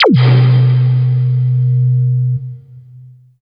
70 BLIP   -R.wav